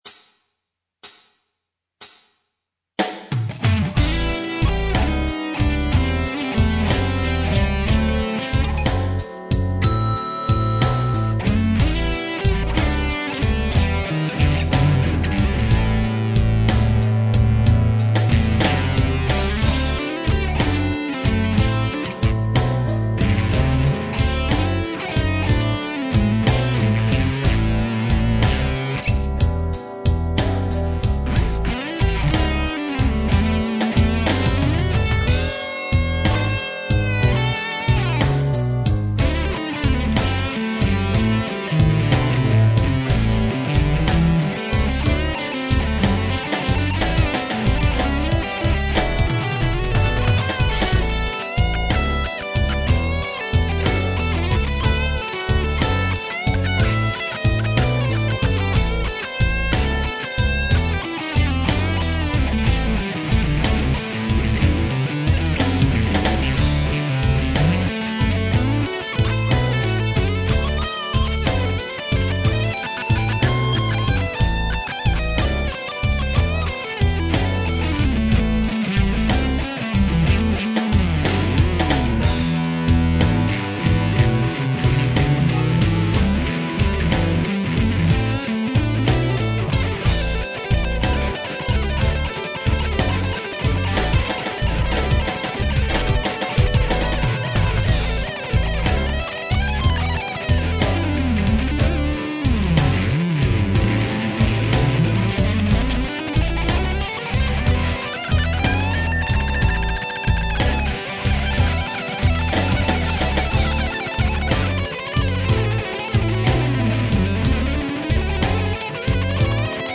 I have taken the 'blues-shredder' approach to these, just for fun.
12-Bar A (Bump/Turnaround):
BluesABumpTurnJAM.mp3